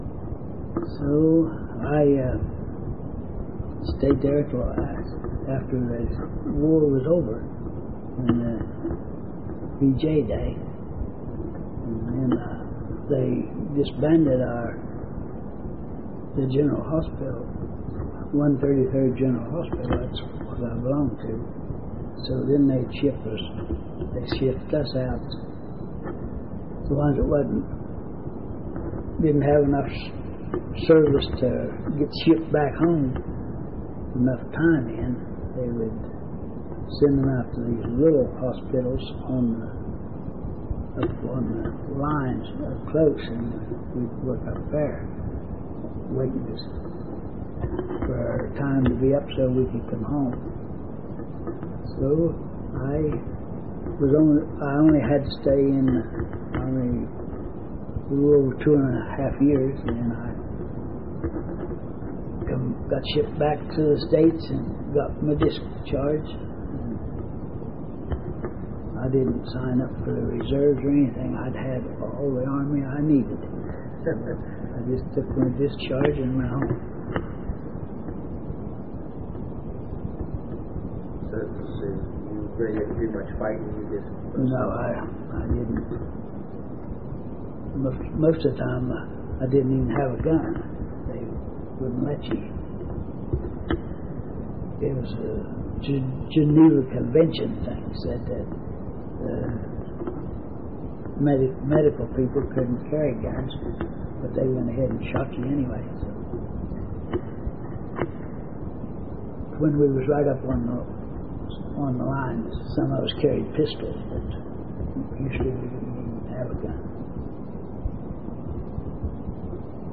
Oral History Collection